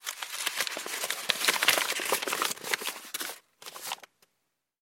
Звуки сворачивания бумаги
Шорох сворачиваемого листа бумаги